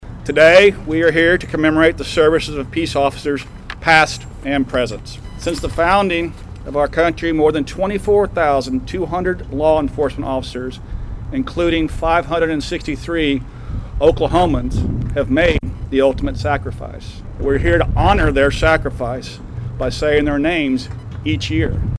The community recognized the peace officers who have died in the line of duty during Wednesday's National Police Week memorial ceremony. Clint Johnson, the U.S. Attorney for the Northern District of Oklahoma, reflected on when President John F. Kennedy officially designated the week to
Clint Johnson on Honoring 5-14.mp3